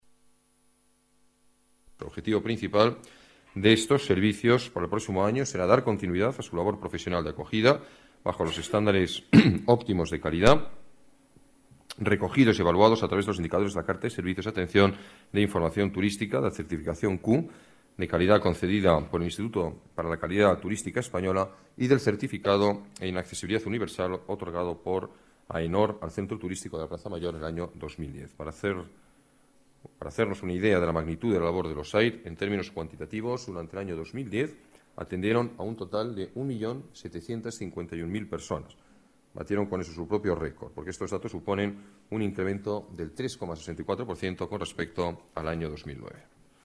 Nueva ventana:Declaraciones alcalde, Alberto Ruiz-Gallardón: atención turística de calidad